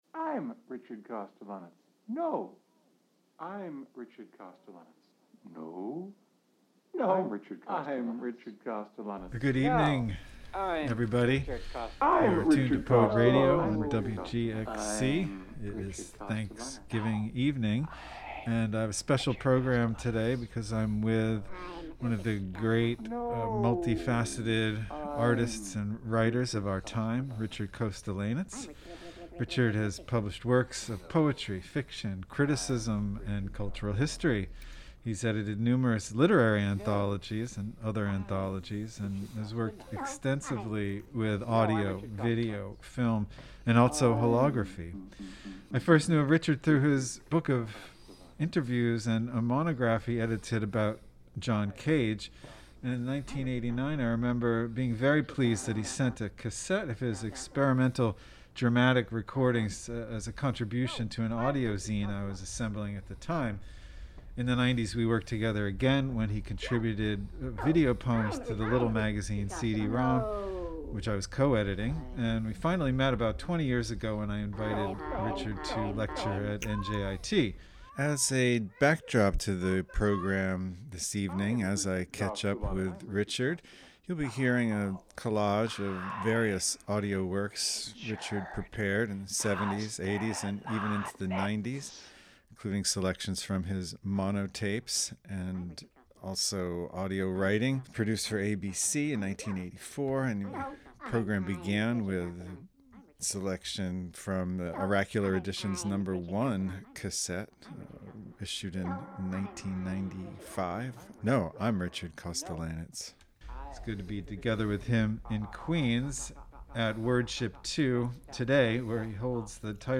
This broadcast features a conversation with poet, writer, avant-garde artist, critic, and curator, Richard Kostelanetz. Over the past half-century, Kostelanetz has produced language-based works using numerous technologies, including audio, film, video, and holography.